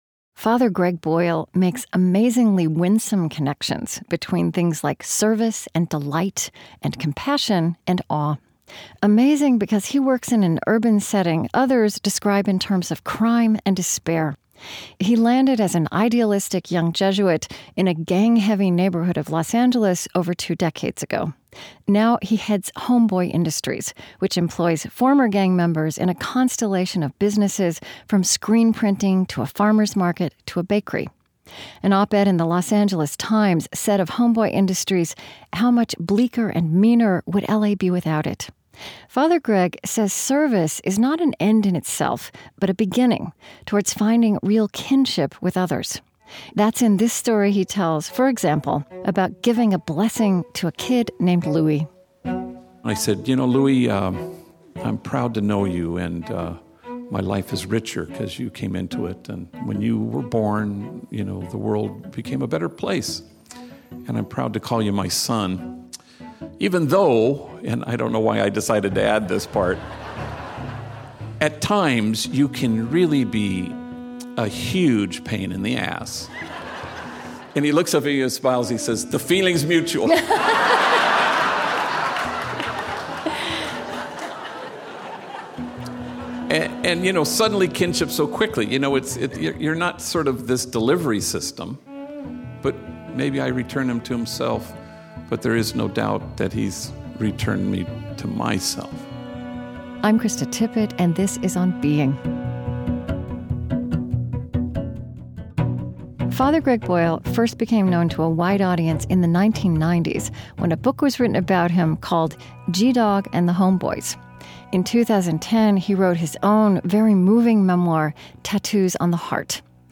This interview is really interesting and Father Boyle is a truly inspirational person, with a lot of excellent observations on compassion, hardship, and relating to one another. Here is the podcast of his interview: